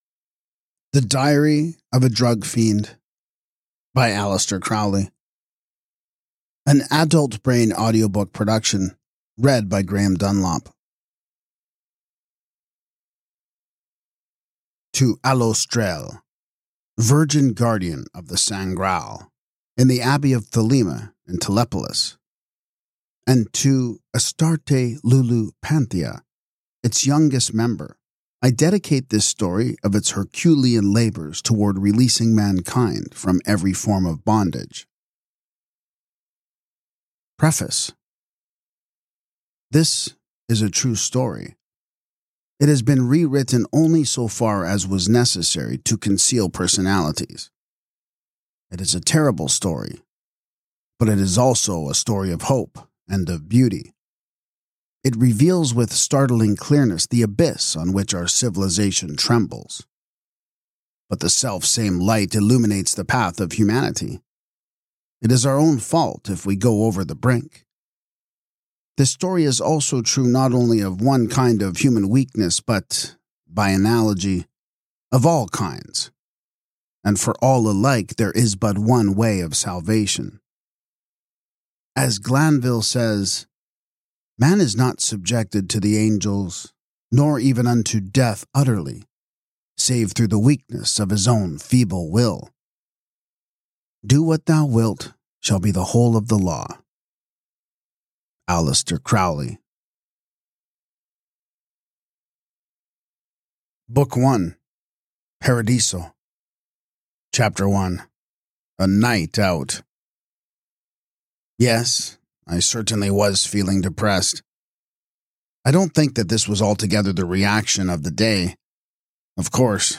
AUDIOBOOK
Narrated with gripping intensity, this audiobook brings to life Crowley’s vivid prose, plunging listeners into the decadent and turbulent world of early 20th-century Europe.